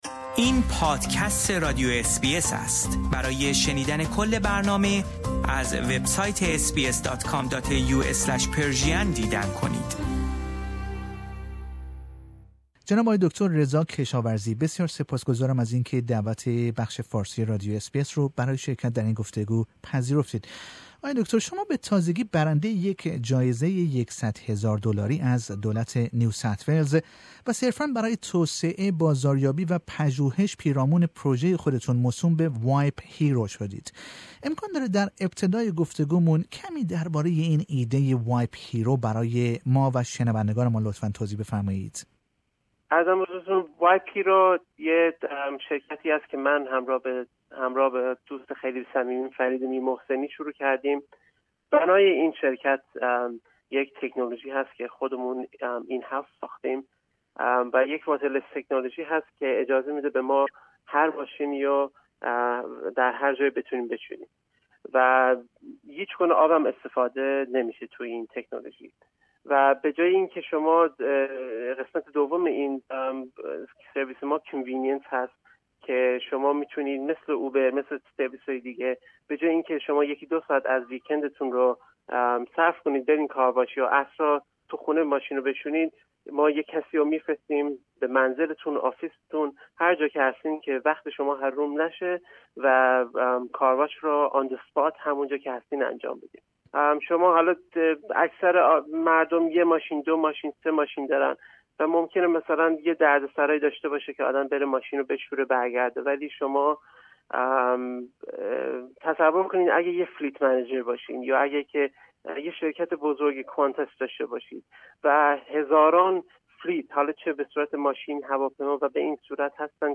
در گفتگو